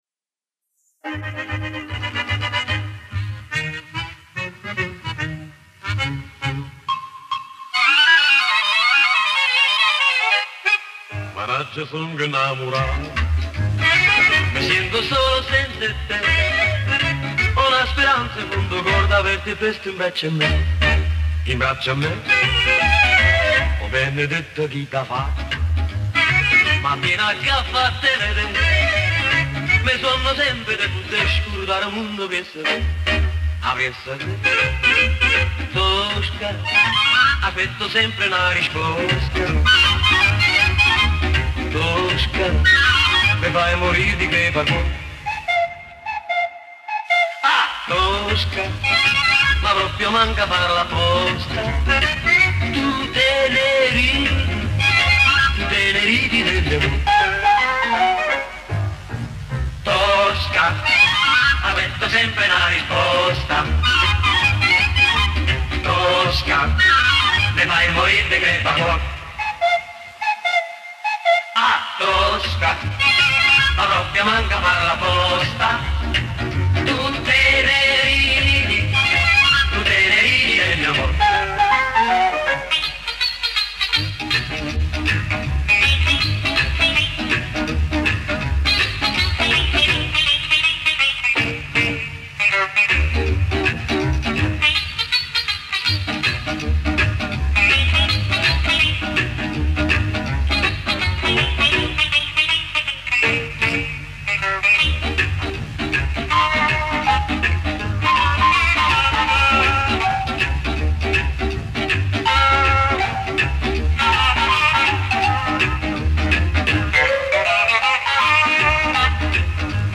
Поет итальянец, даже наполетанец. Акцент явно их.